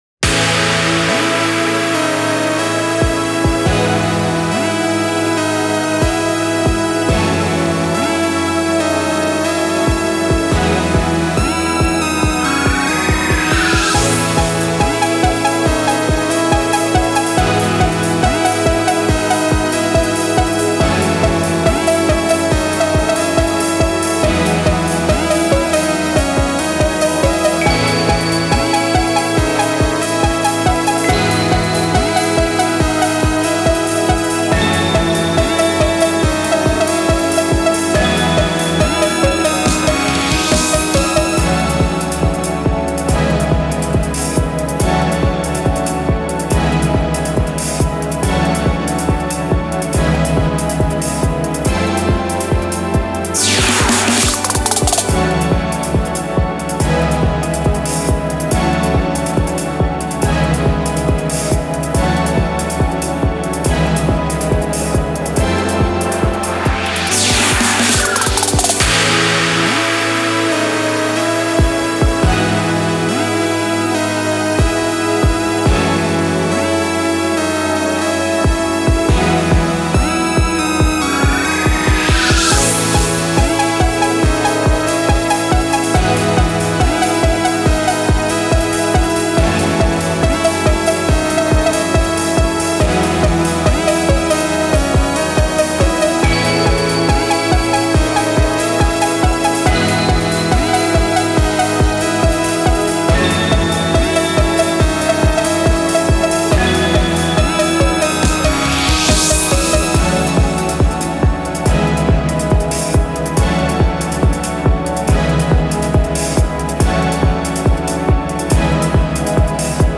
EDM
ポップス
シンセ
打楽器
明るい